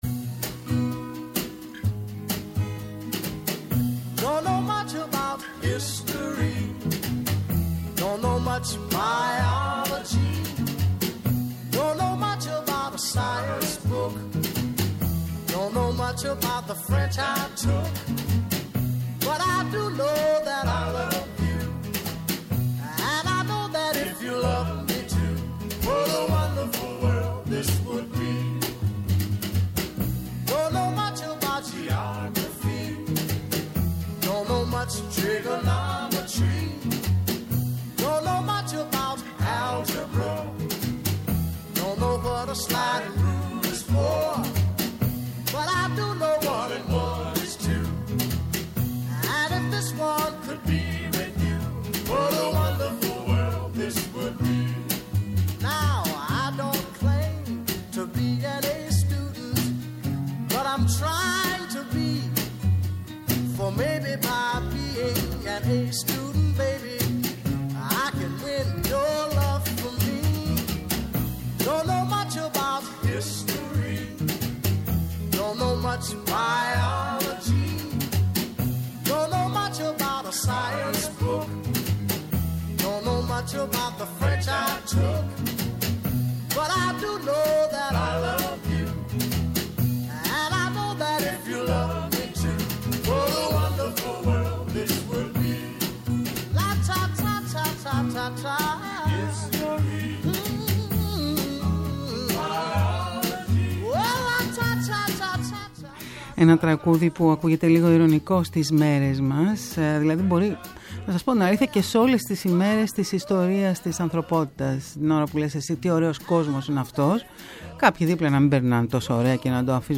Η Σωστή Ώρα στο Πρώτο Πρόγραμμα της Ελληνικής Ραδιοφωνίας